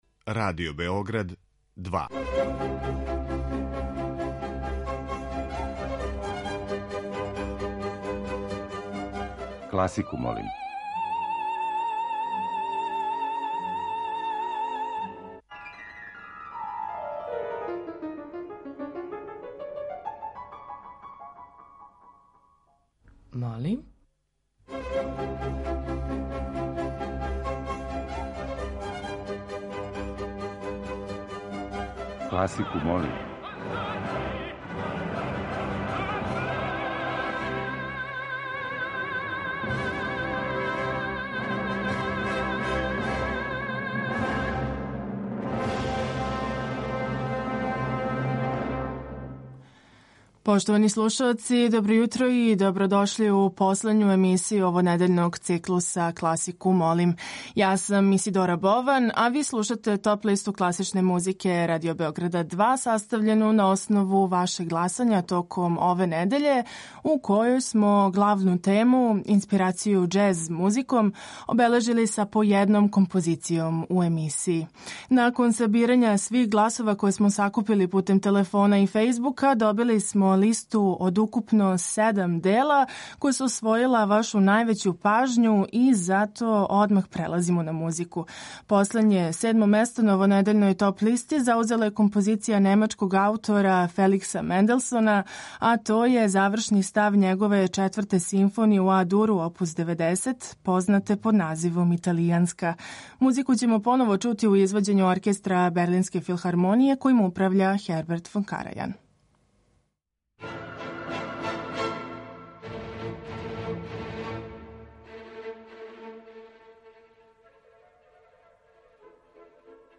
Топ-листа класичне музике